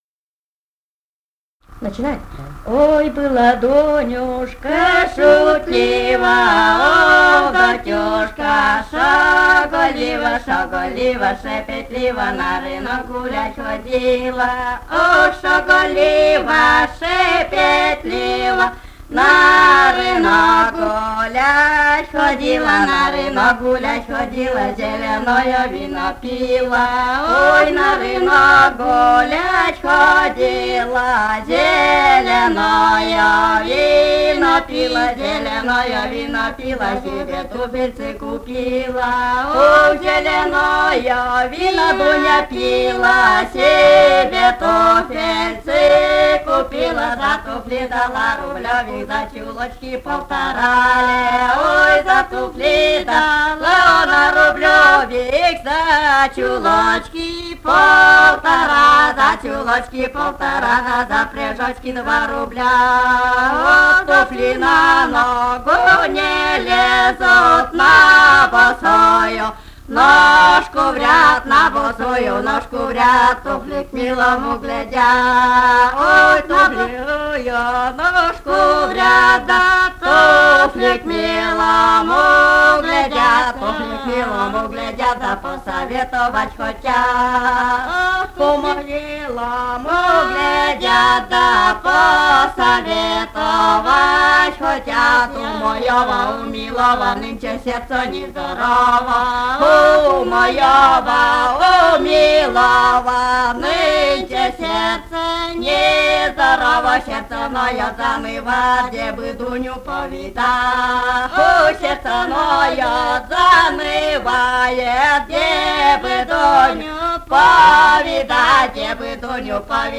полевые материалы
«Ой, была Донюшка шутлива» (плясовая шуточная).
Пермский край, д. Пепеляево Очёрского района, 1968 г. И1077-30